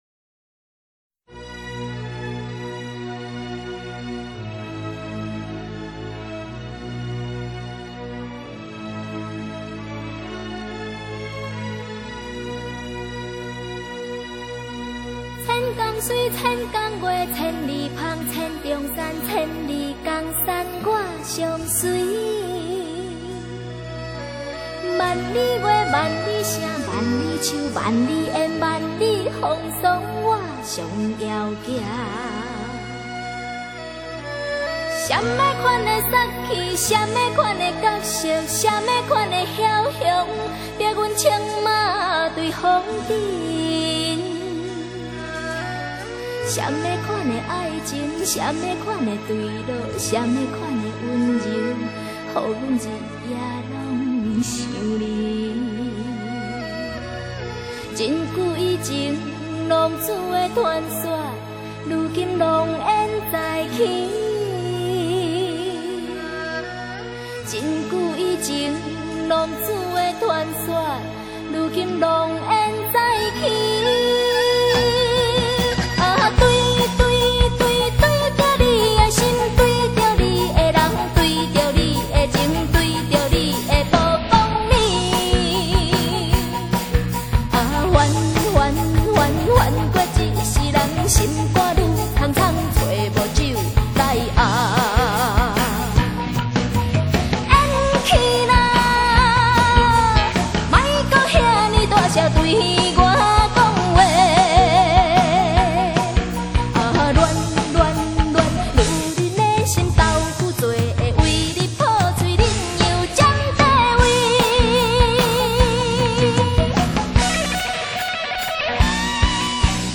这专辑不错，女声声音很好听，就是从头到尾就听到追了，别的没听懂